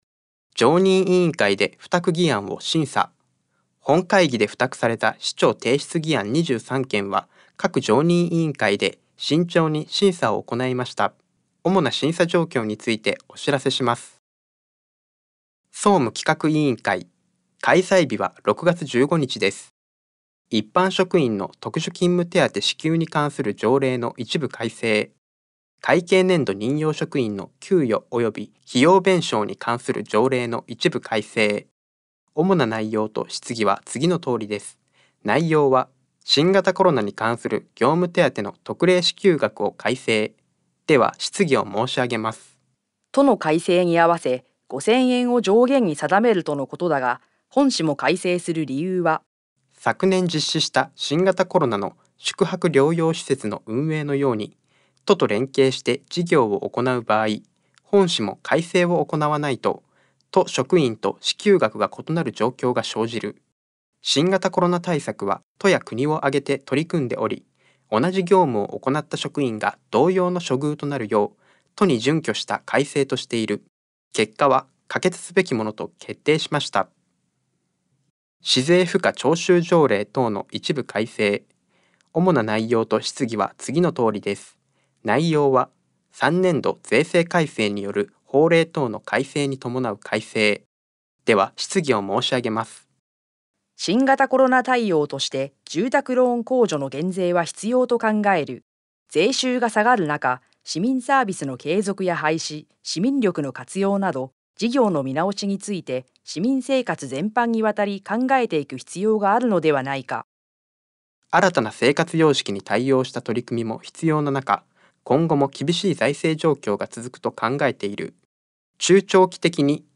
「声の市議会だより」は、視覚に障害のある方を対象に「八王子市議会だより」を再編集し、音声にしたものです。